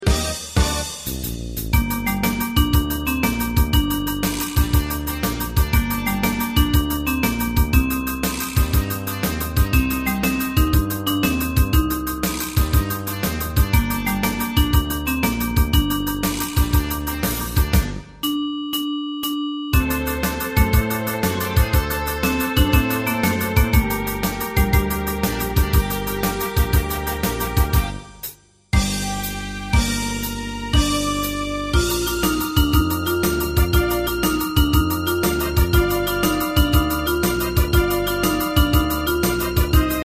大正琴の「楽譜、練習用の音」データのセットをダウンロードで『すぐに』お届け！
カテゴリー: ユニゾン（一斉奏） .
歌謡曲・演歌